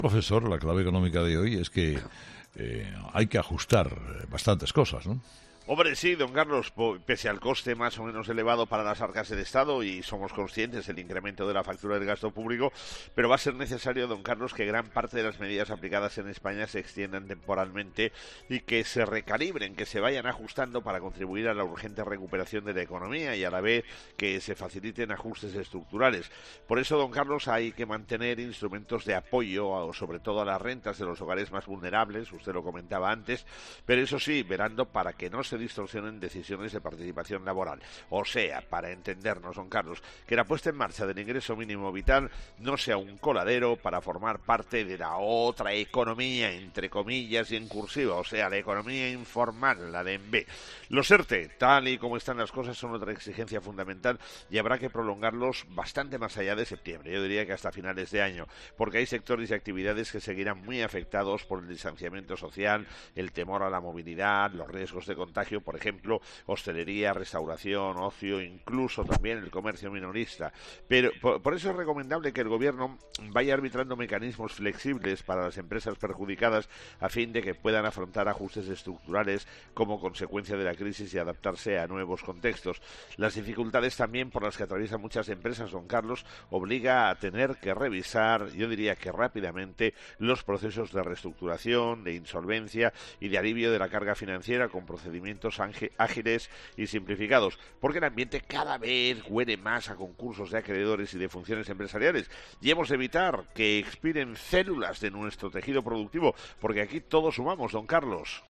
El profesor José María Gay de Liébana analiza en 'Herrera en COPE’ las claves económicas del día